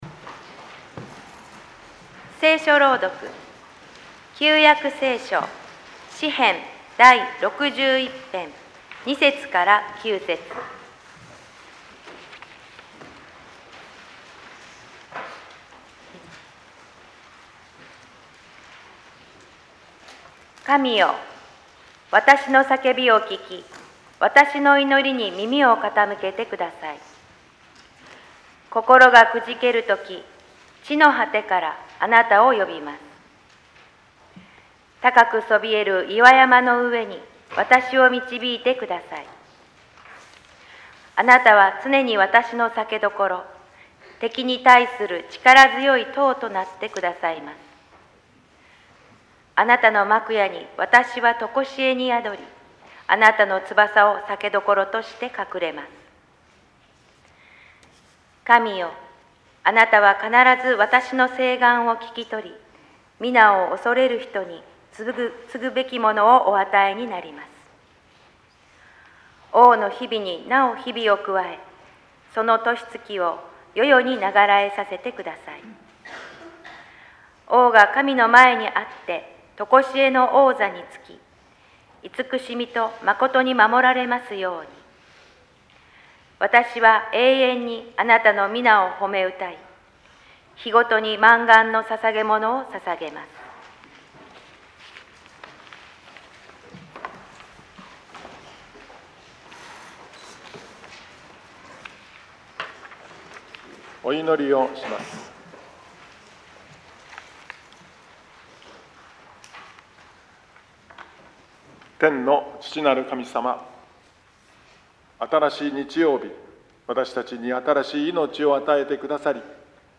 総員礼拝と子ども祝福式
説 教